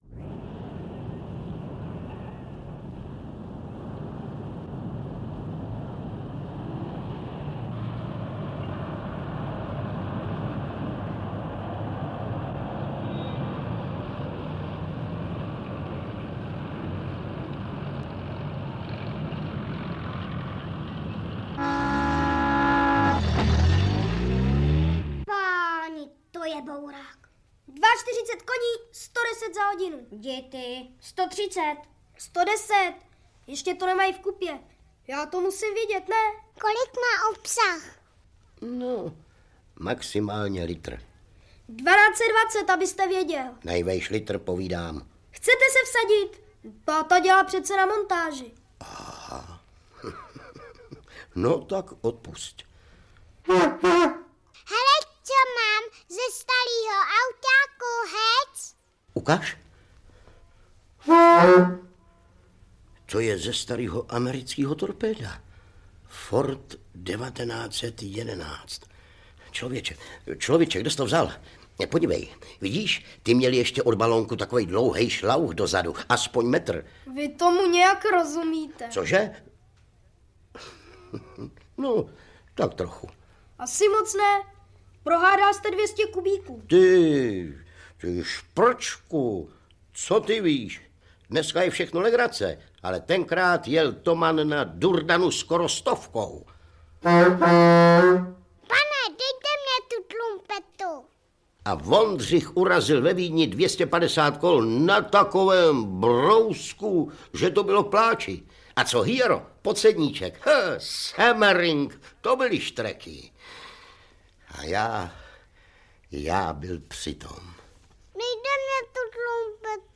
Příběh pro mládež podle motivů z knihy Dědeček automobil - Adolf Branald - Audiokniha